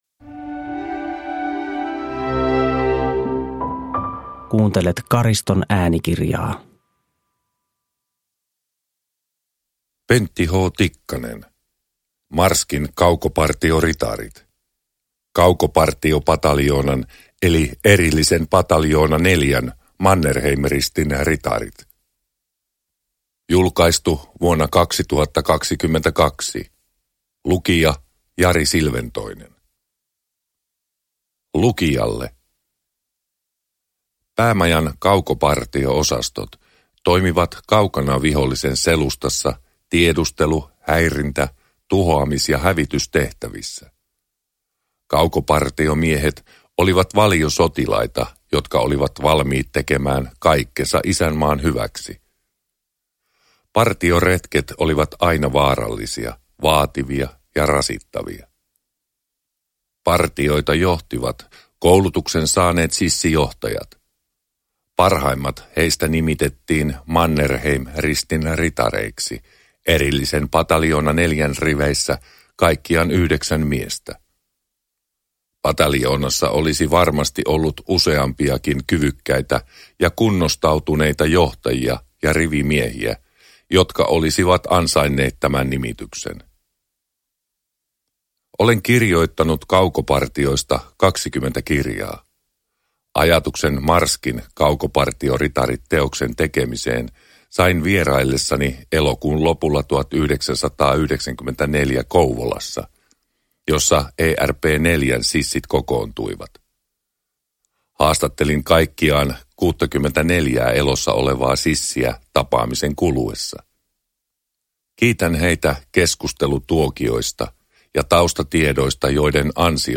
Marskin kaukopartioritarit – Ljudbok – Laddas ner